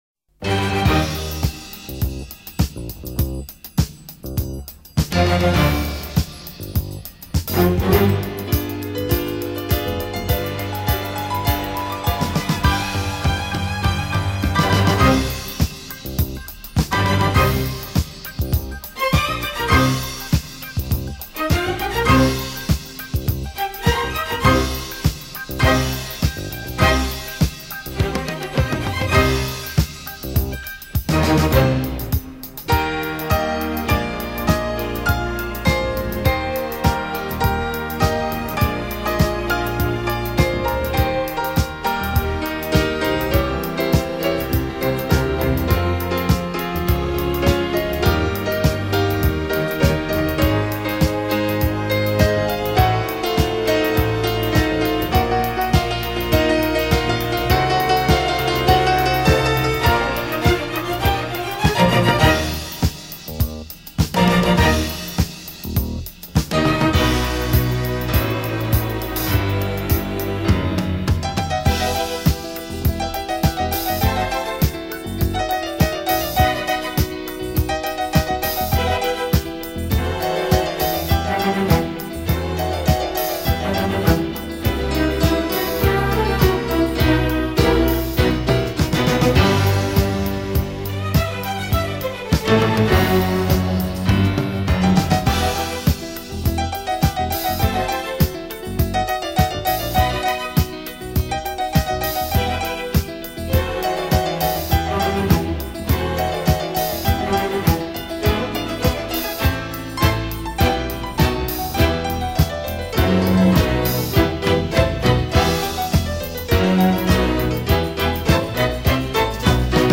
手指微妙触及琴键所散发出的音符，充满罗曼蒂克式的醉人芳香将你的